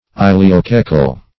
ileocaecal.mp3